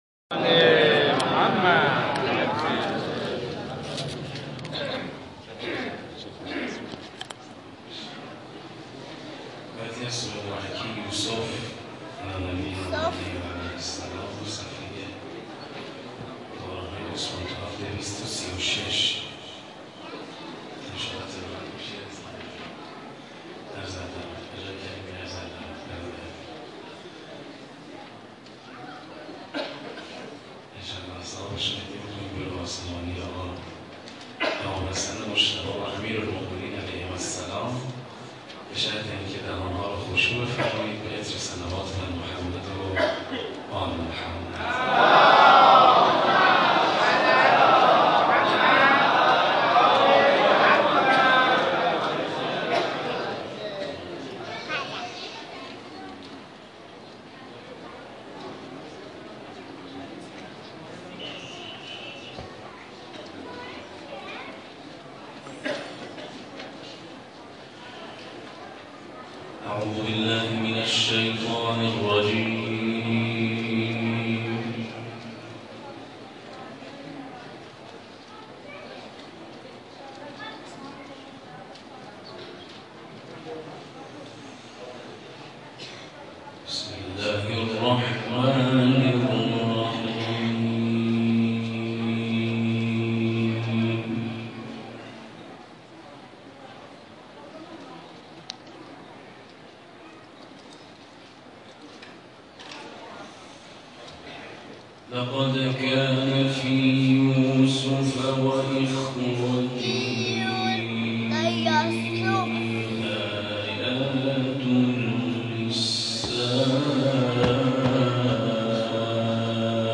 محفل انس با قرآن
گروه جلسات و محافل ــ شهرستان ساوه در شب گذشته میزبان قاریان و حافظان ممتاز کشور بود.